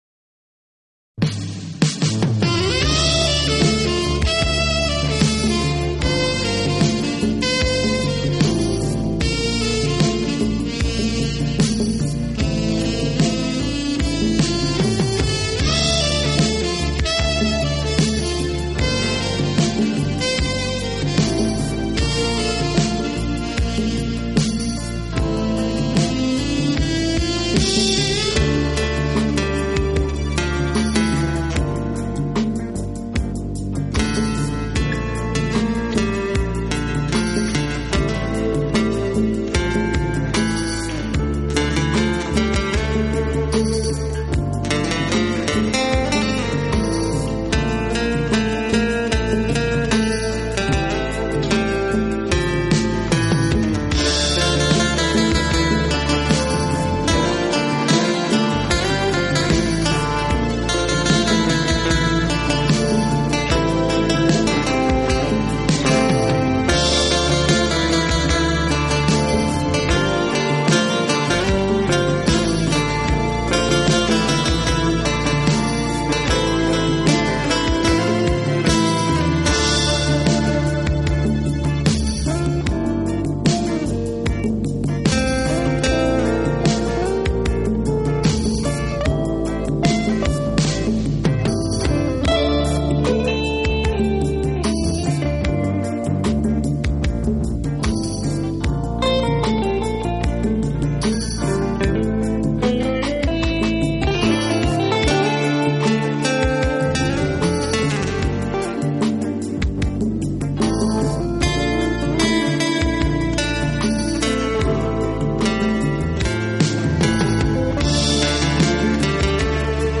【轻音乐】
轻音乐作品的旋律优美动听、清晰流畅，节奏鲜明轻快，音色丰富多彩，深受